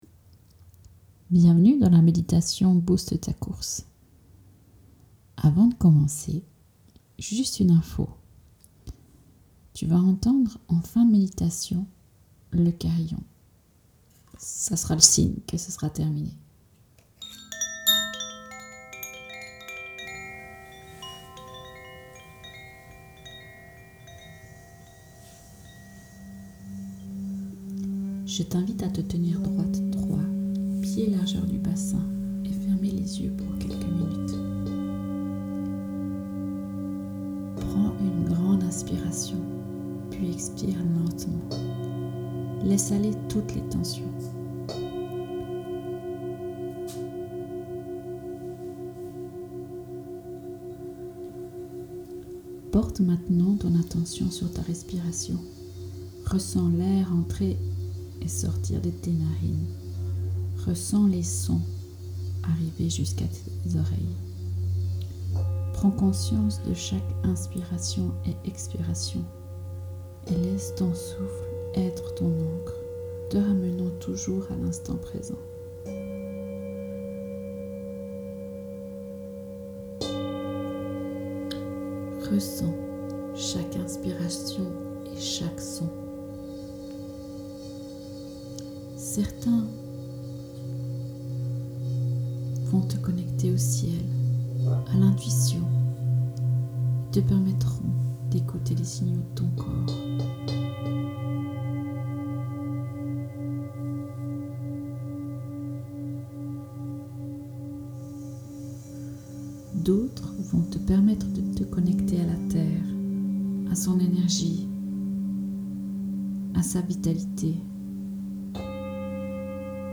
par | Mai 2, 2025 | Bain sonore, Bols chantants planétaires | 0 commentaires
Une méditation accompagnée de bols chantants planétaires